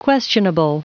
Prononciation du mot questionable en anglais (fichier audio)
Prononciation du mot : questionable